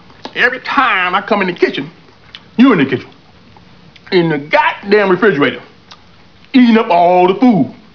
here are some sounds from my favorite movie of all time, friday...you should set them up so they come on when you start and shut down your computer. always good for a laugh.